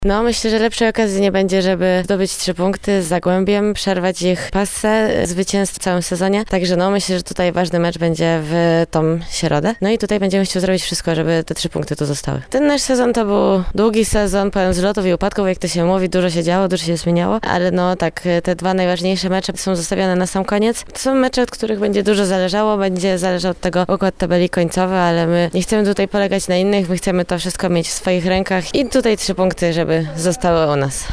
Wypowiedzi przedmeczowe